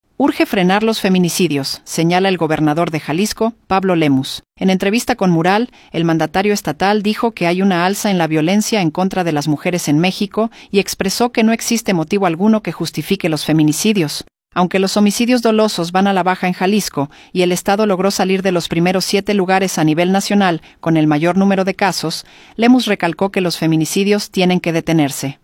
entrevista-7.m4a